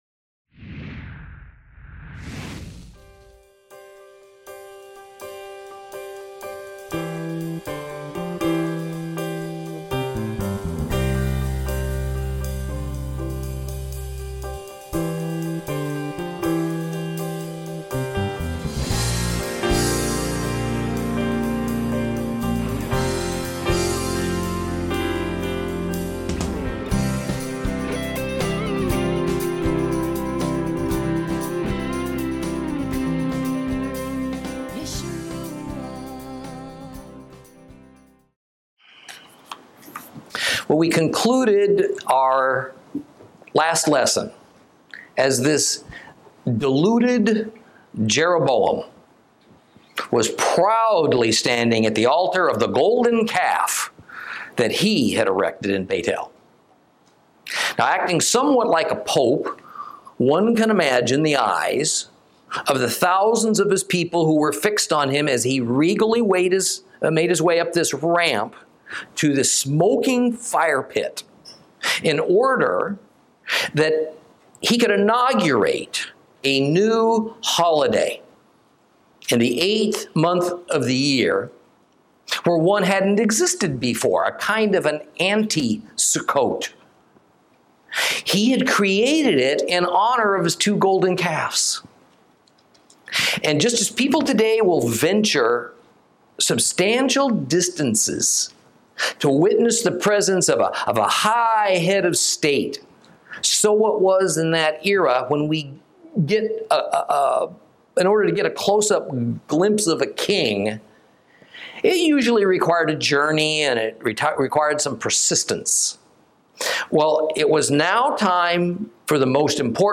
Lesson 23 Ch13 - Torah Class